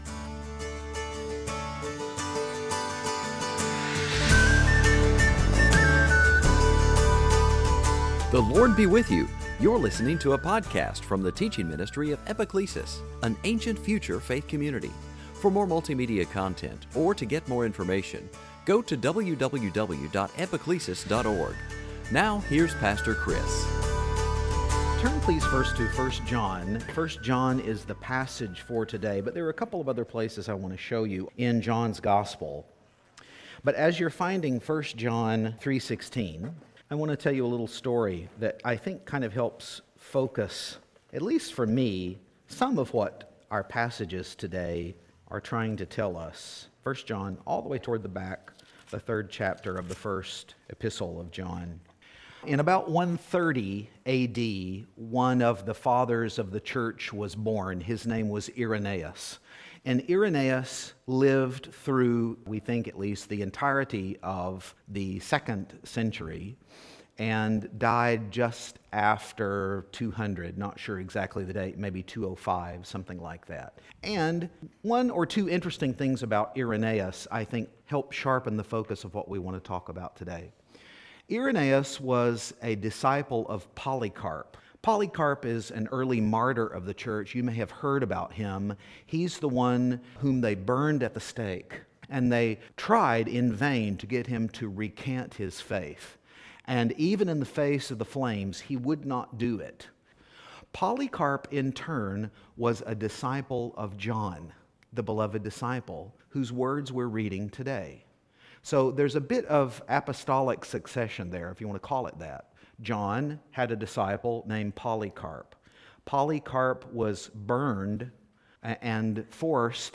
Series: Sunday Teaching This post-Easter season we’ve been talking about looking through the Sunday Scriptures through the lens of